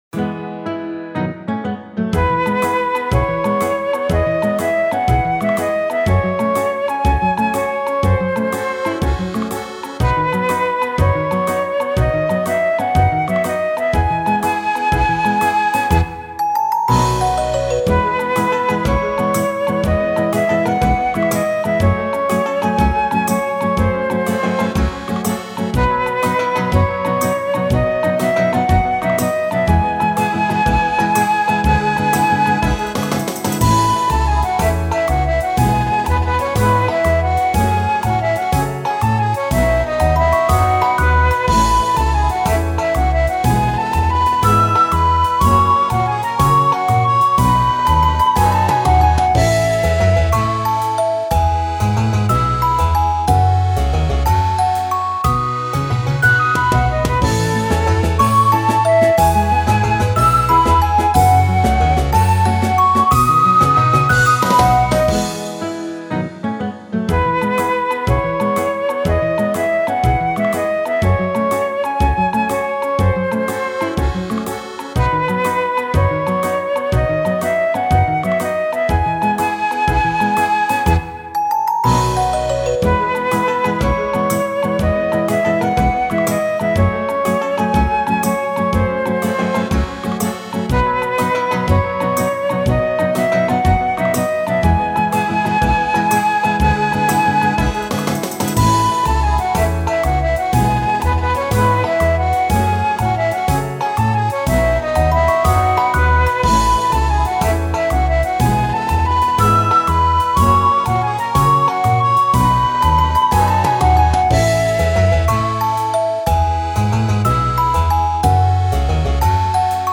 ogg(L) 村 おだやか 明るい フルート
牧歌的で温かみのある曲調に乗せてフルートがまったりと歌う。